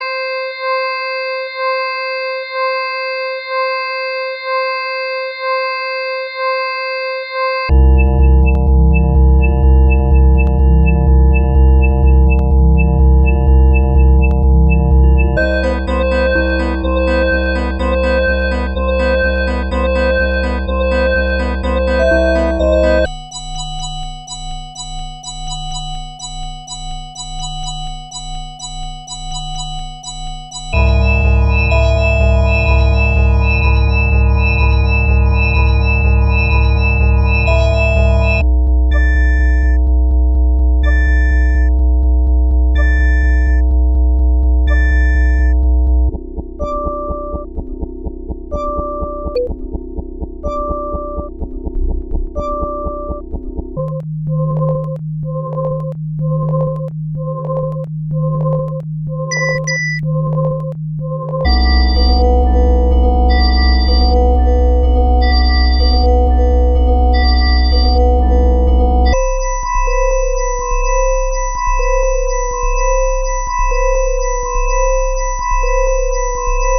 We have developed a computer algorithm that creates, breeds and mutates short pieces of music.
At the end of the experiment, we hope to have a population of music that is far more "musical" than the starting population which was founded by two random pieces of music (we call them Adam and Eve, but in DarwinTunes music has sex, but is genderless) built entirely from sine waves.
darwintunes-primordial-sounds1.mp3